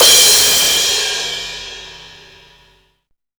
CRASHDIST2-L.wav